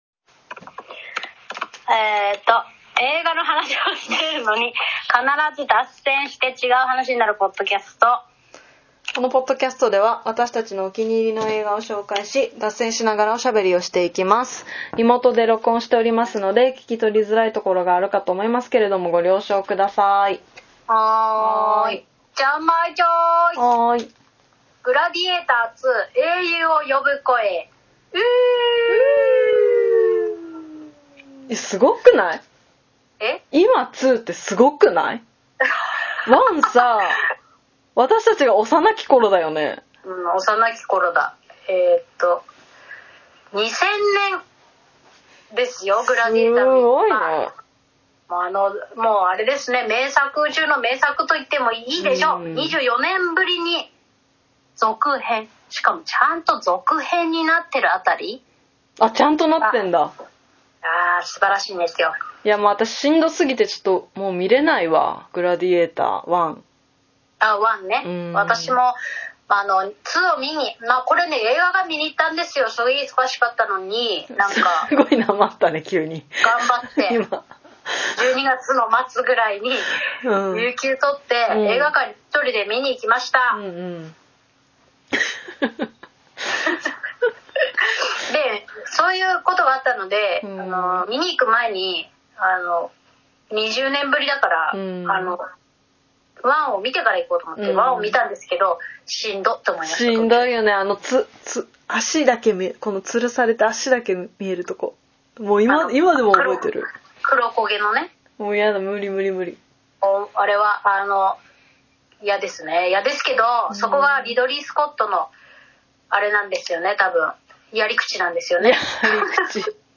(現在はリモート収録中)毎週月曜日に更新しています。